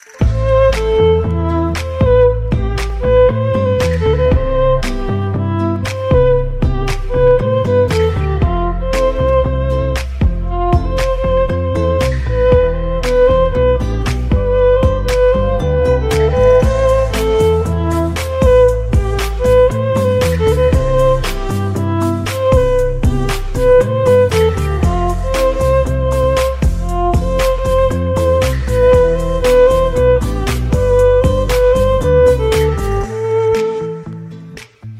Violinist | Feature Act